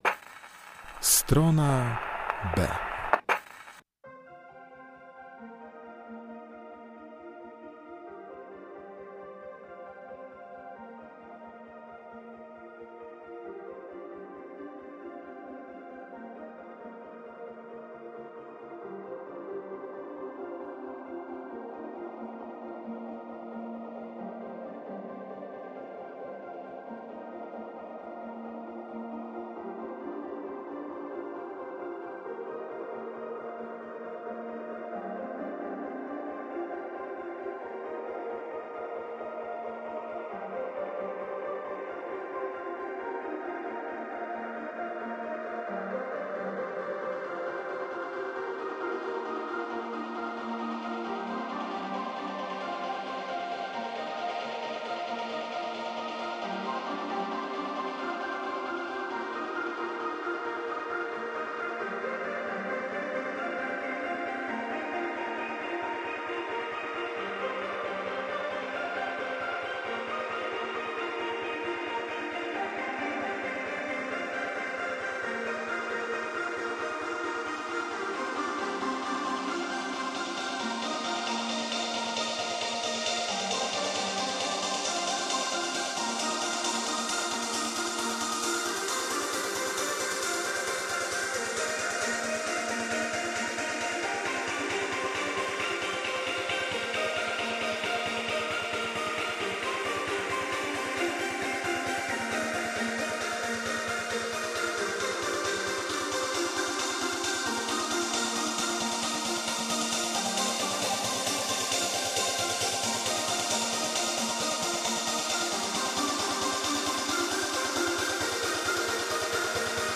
STRONA B - godzina z elektroniką, ambient, post punkiem i shoegaze.